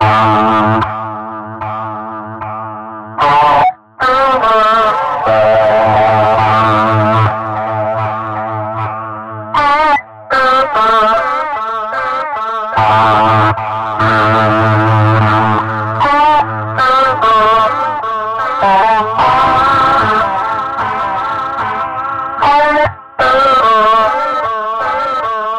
原声Lofi 嘻哈大鼓
Tag: 91 bpm Hip Hop Loops Drum Loops 1.77 MB wav Key : Unknown